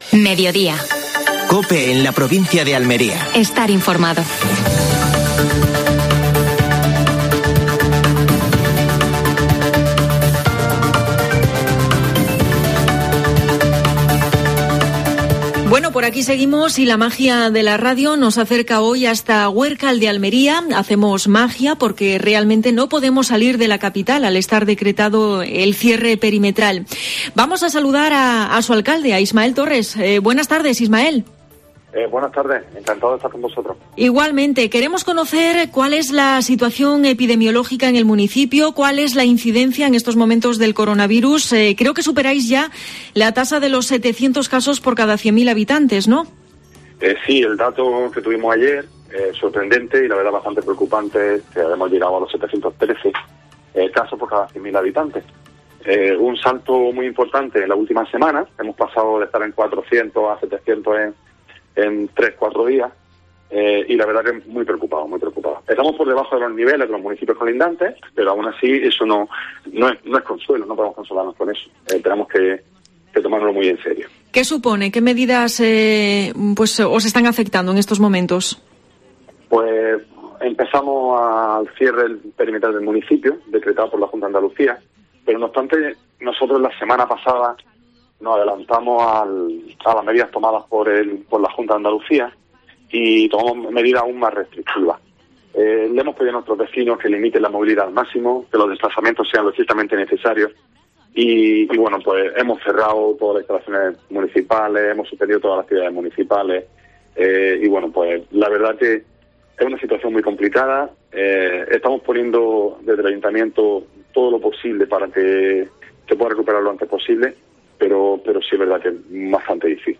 Entrevista a Ismael Torres (alcalde de Huércal de Almería). La COVID-19 en el deporte almeriense.